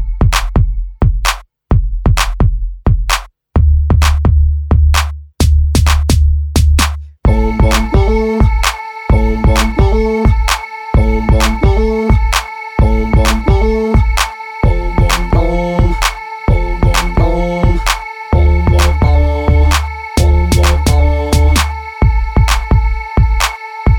No Backing Vocals R'n'B / Hip Hop 4:07 Buy £1.50